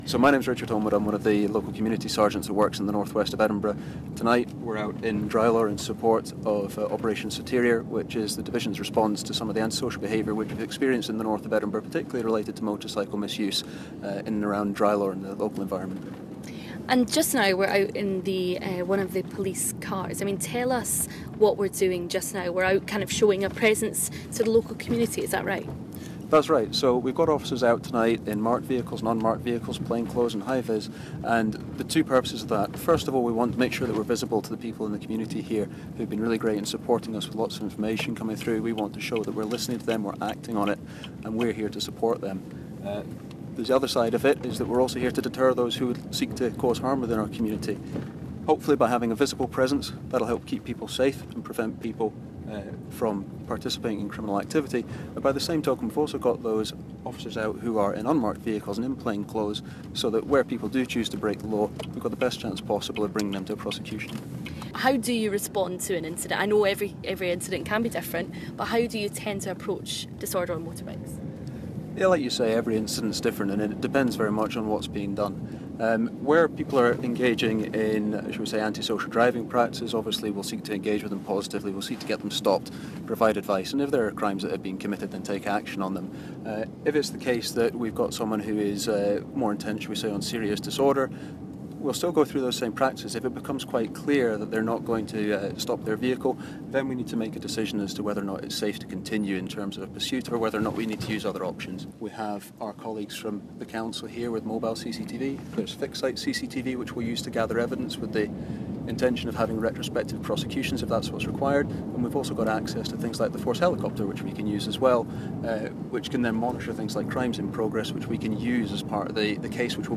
as he patrolled north Edinburgh last Friday night